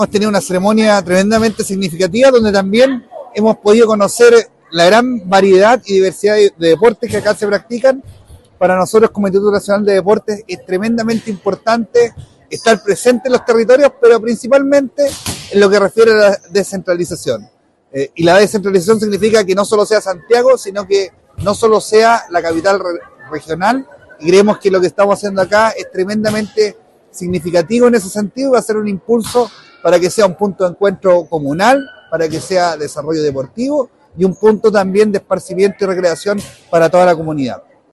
Finalmente, el director nacional del Instituto Nacional del Deporte, Israel Castro, explicó que con estos proyectos se busca descentralizar el desarrollo deportivo en el país: